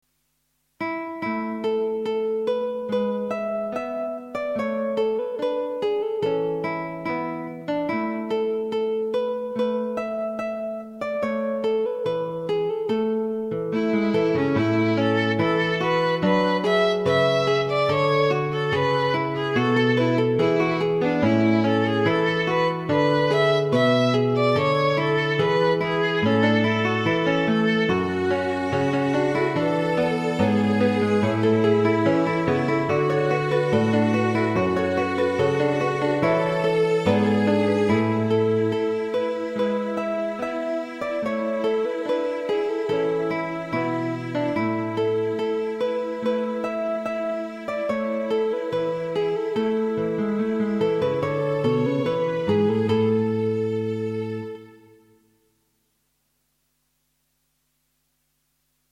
klassiek
Kinderlijk muziekstukje met gitaar en viool